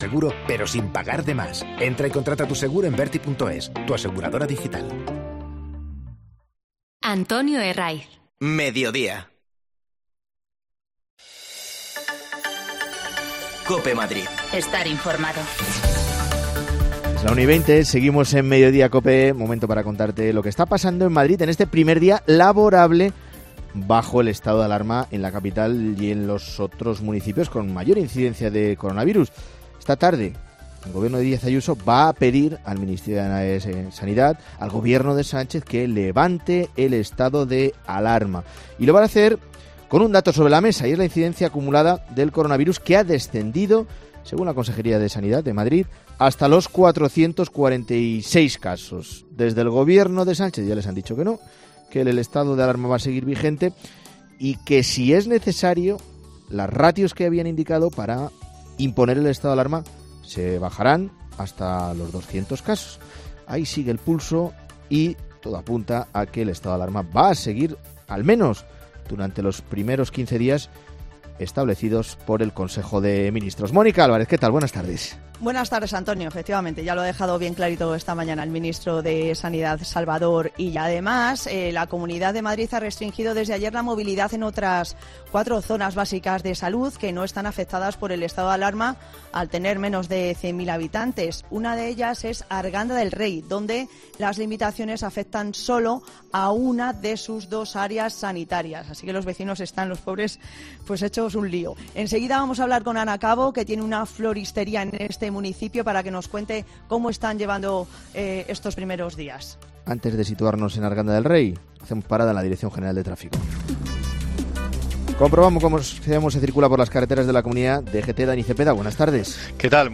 Nos vamos a Arganda del Rey, una de las localidades con confinamiento selectivo desde ayer. Hablamos con una vecina para que nos cuente cómo lo llevan